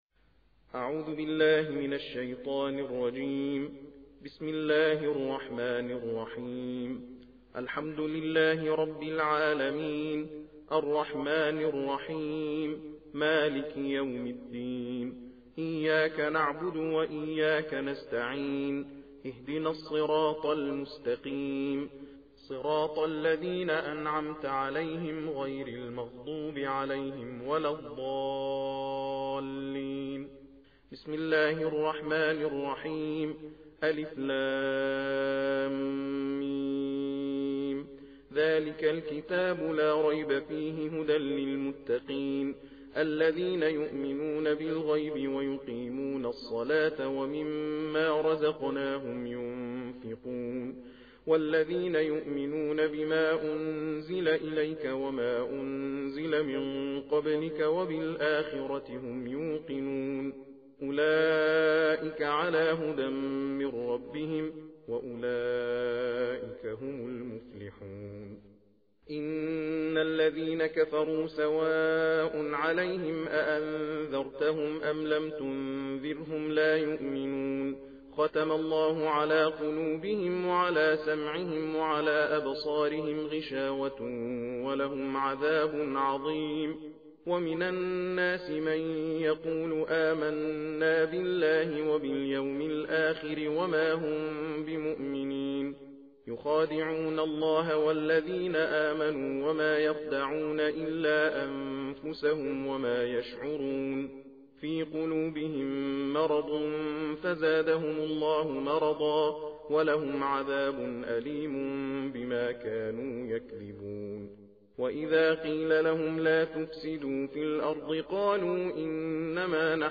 قرائت جزء اول قرآن
تلاوت قرآن کريم